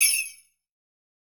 Tambourine Rnb 1.wav